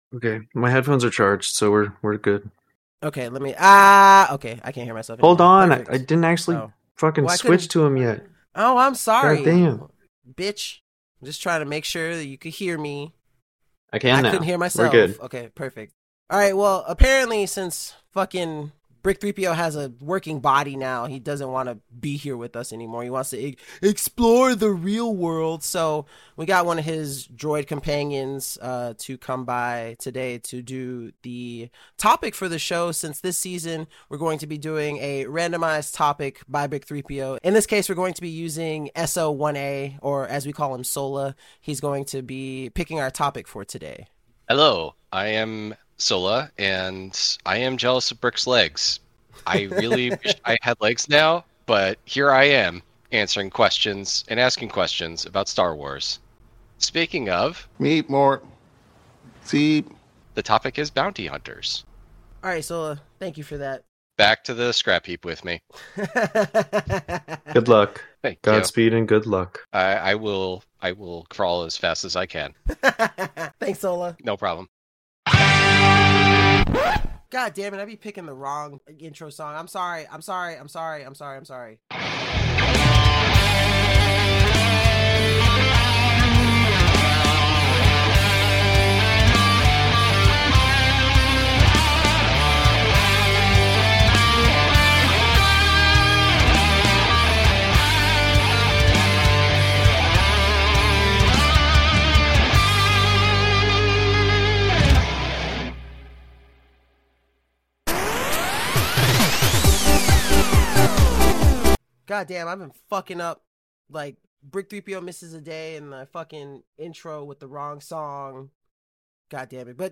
Four friends explore, discover and unravel the mysteries of the Star Wars universe, diving into both the Canon and the Legends timeline to give you all the Star Wars content you never knew you needed.